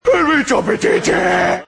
AUDIO: Crackovia imita a Manolo Lama y sus narraciones: 'El Bichoooooo'.